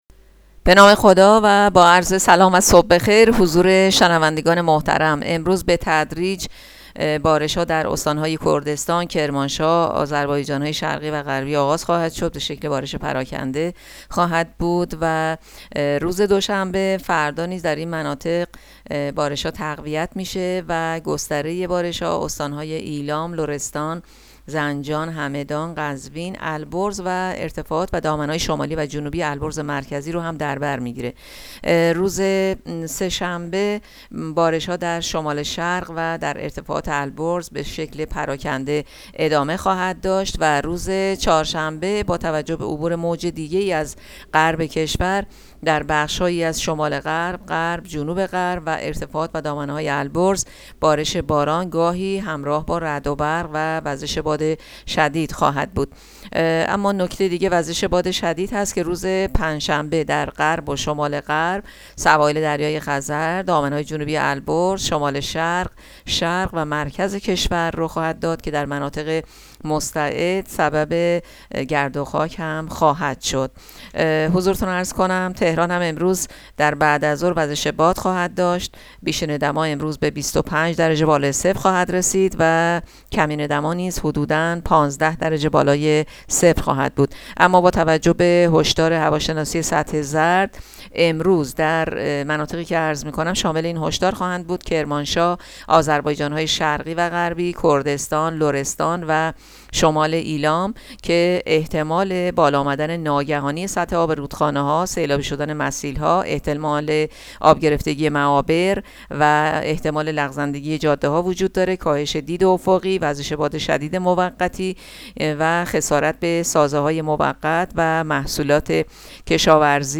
گزارش رادیو اینترنتی پایگاه‌ خبری از آخرین وضعیت آب‌وهوای ۱۰ فروردین؛